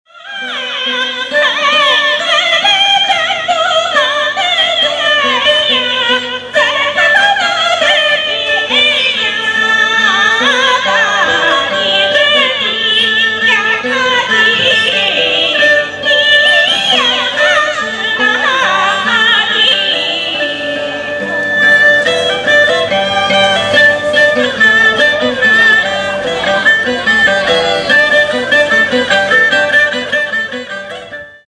Of course, there are always musicians with over-amplified singers (click to listen to
Woman-Singer.mp3